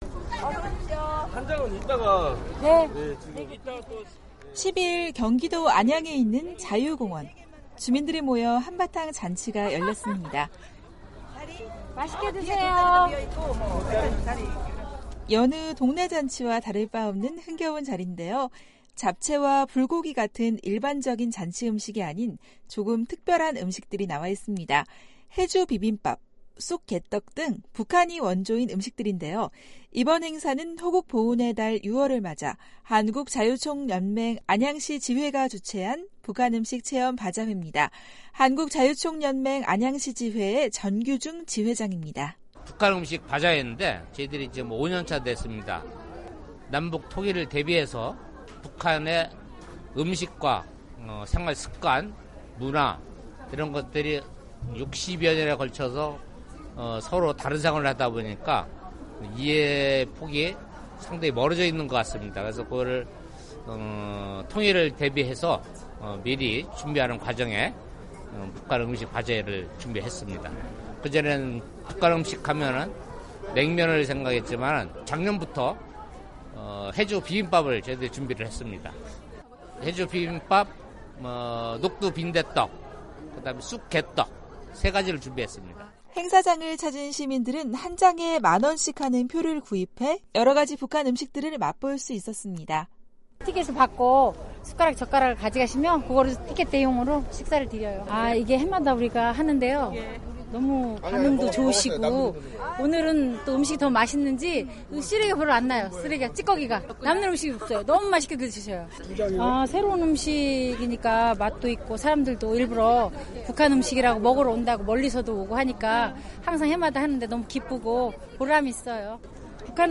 지난 10일 경기도 안양 자유공원에서 북한 음식 바자회가 열렸다.